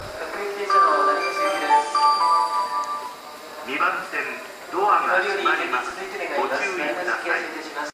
スピーカーはNational型が使用されており 音質がとてもいいですね。
発車メロディーフルコーラスです。降りた電車で収録しました。